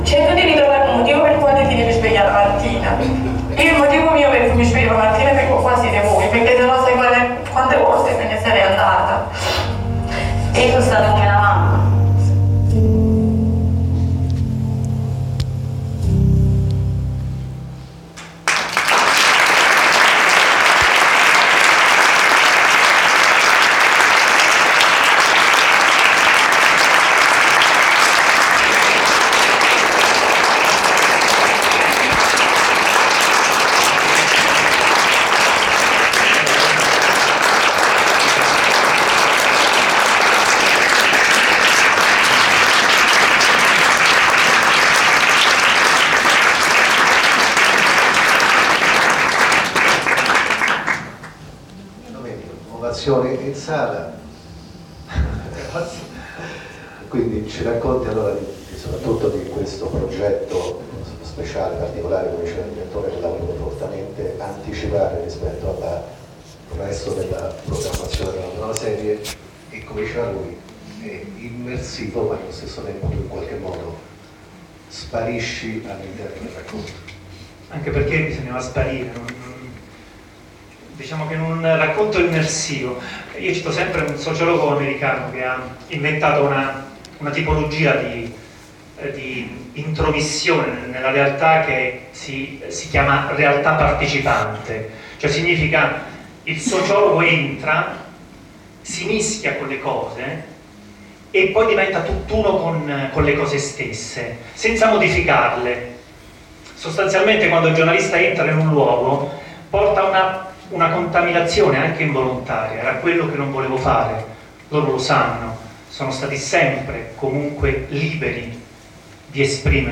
Lo speciale Come figli miei è stato presentato alla stampa lunedì 22 ottobre nella sede RAI di Viale Mazzini a Roma.
Ecco l’intervento completo di Domenico Iannacone, a seguito della proiezione del trailer: